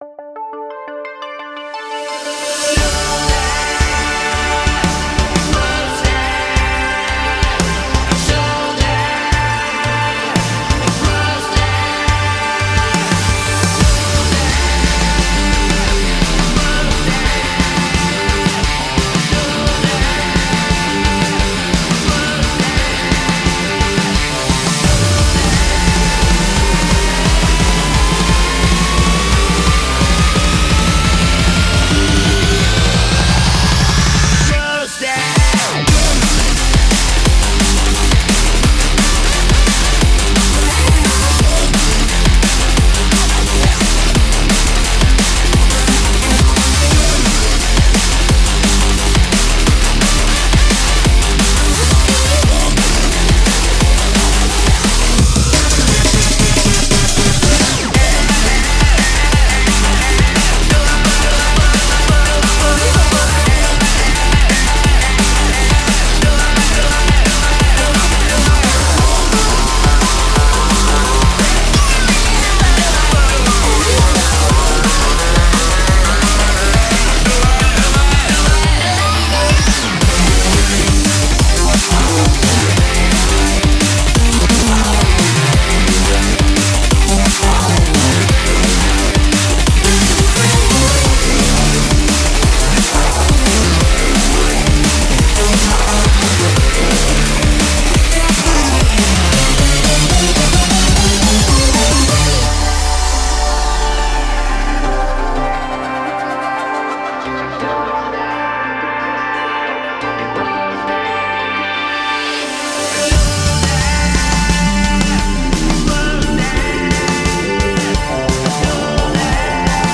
BPM174
Audio QualityCut From Video